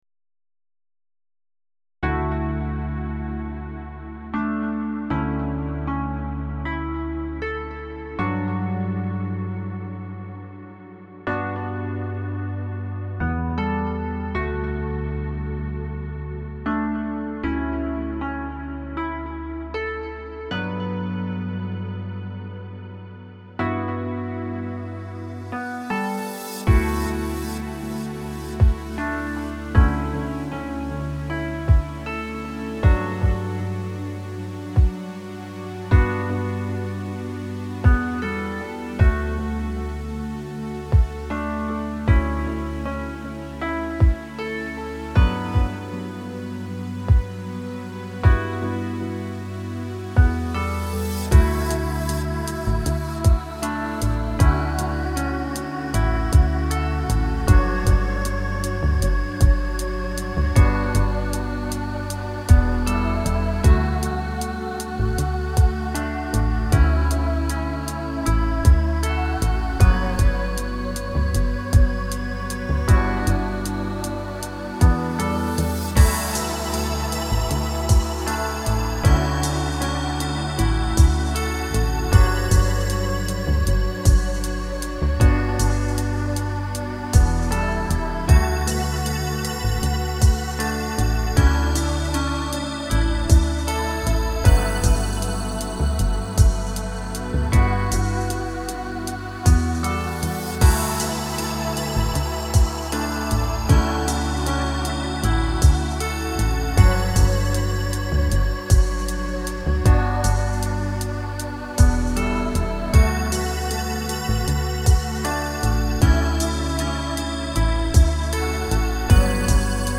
Downtempo/Chillout, Ambient Genre